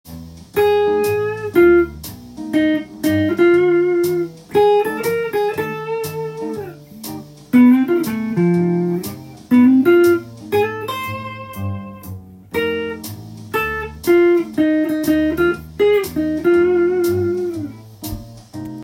以上のフレーズを適当に並べてソロを弾いてみました。